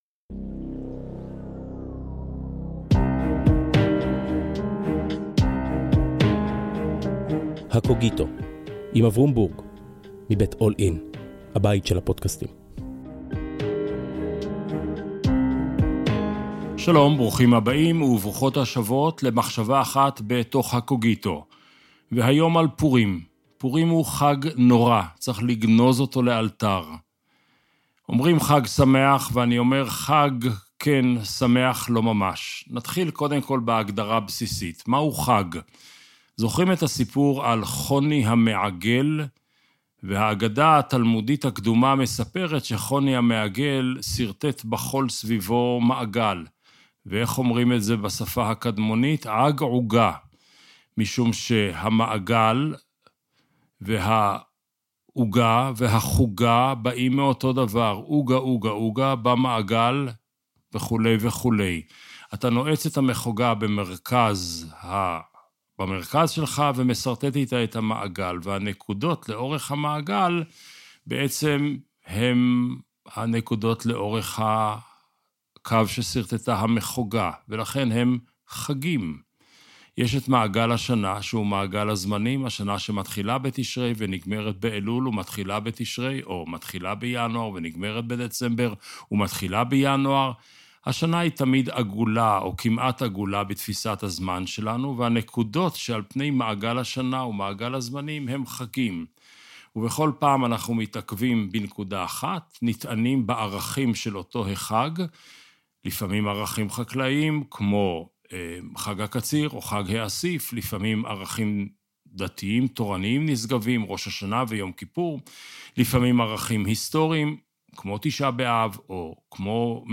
בפודקסט שבועי משוחח אברום בורג עם דמות מובילה אחרת בשדה התרבות והרוח על הטקסט המכונן של חייה. שיחה לא שיפוטית, קשובה אבל מאתגרת.